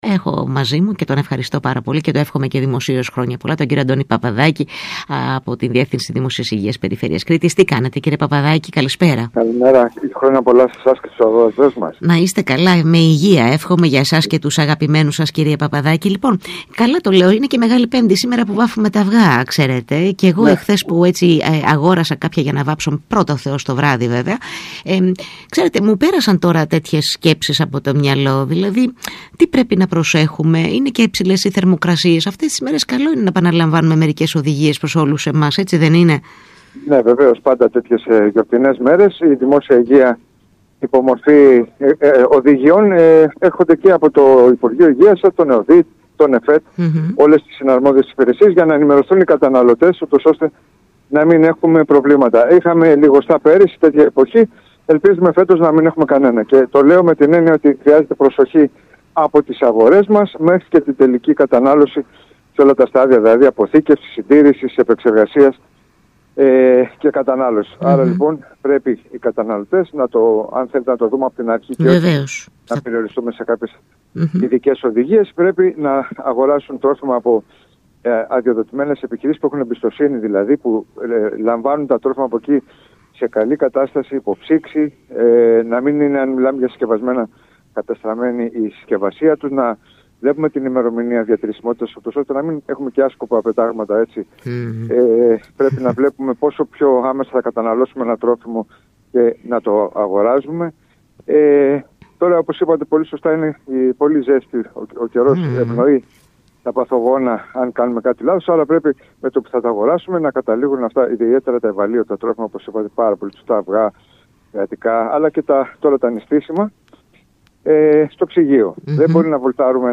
μιλώντας στον ΣΚΑΙ Κρήτης 92